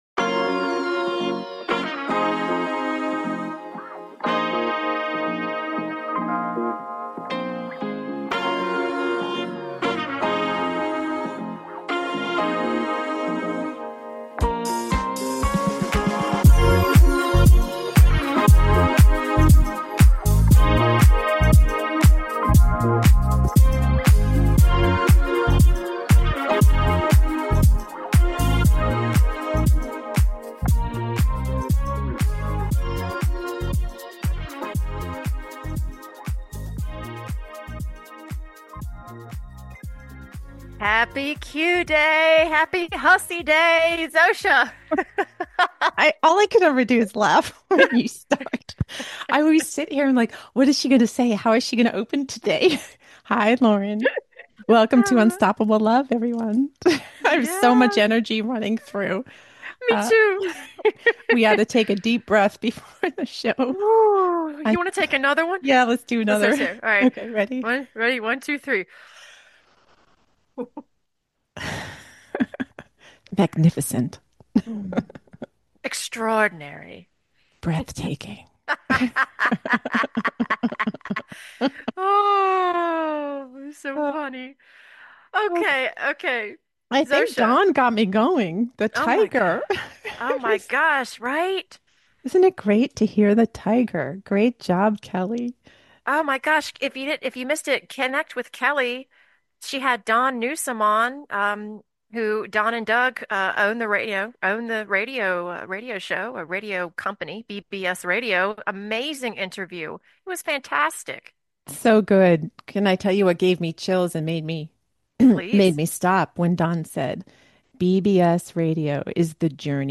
CLICK HERE SUBSCRIBE TO TALK SHOW